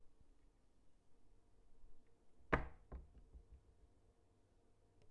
弗利 " 橱柜2
描述：关橱柜门。
Tag: 打开 橱柜 关闭 Tascam的 现场记录仪 DR-40